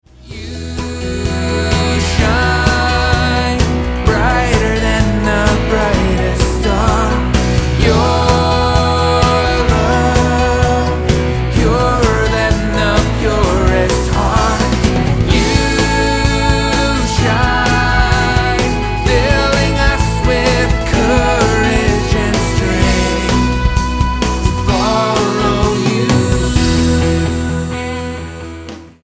STYLE: Pop
Recorded live at St. Catherine's Church in Dublin, Ireland